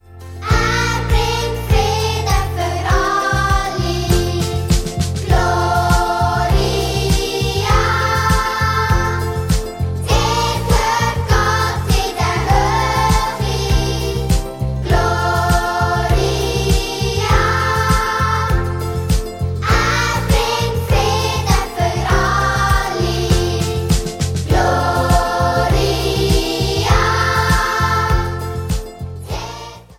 Weihnachtsmusical